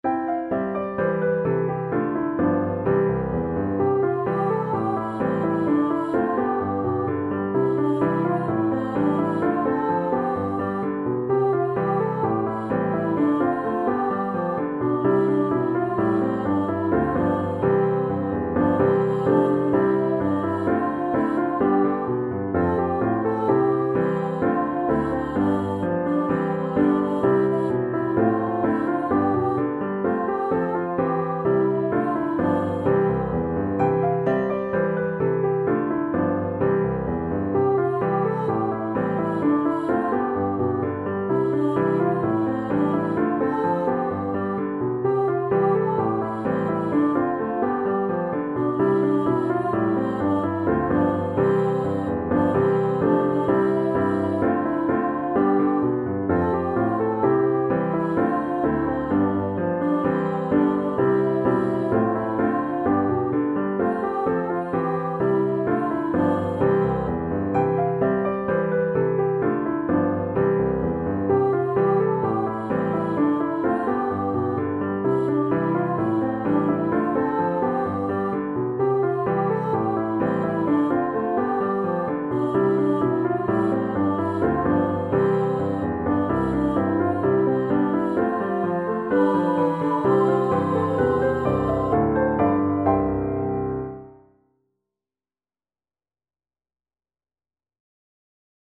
Backing Track
Cycle-and-Recycle-Backing-Track.mp3